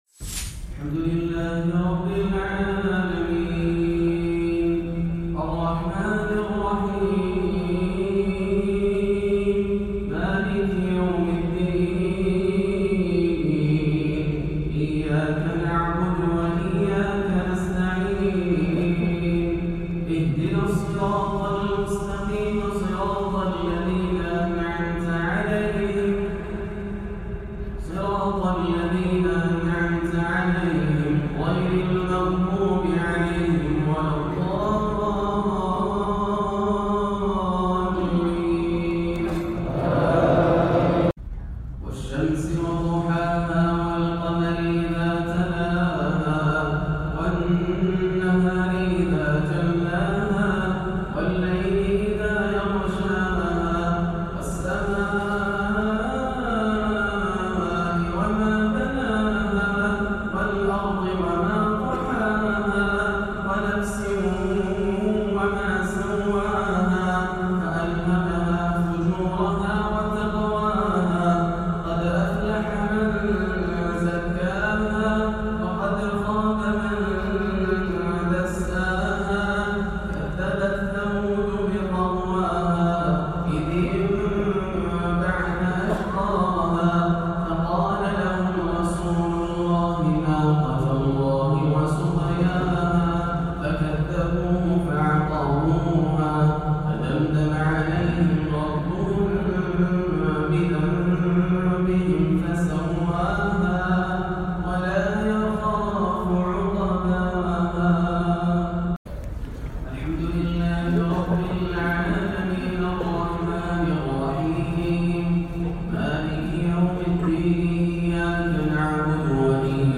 (فَأَنذَرْتُكُمْ نَارًا تَلَظَّىٰ) صلاة الجمعة 4 صفر 1438هـ سورتي الشمس و الليل > عام 1438 > الفروض - تلاوات ياسر الدوسري